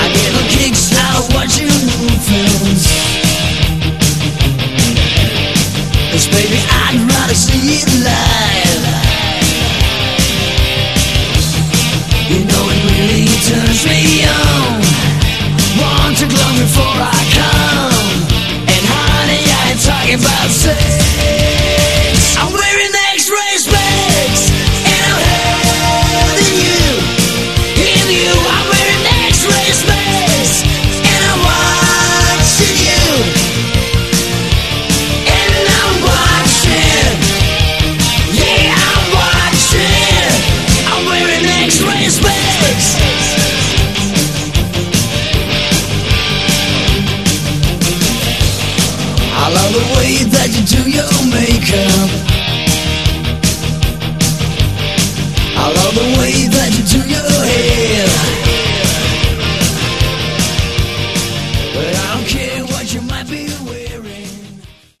Category: Hard Rock
lead vocals
guitars, bass, keyboards
drums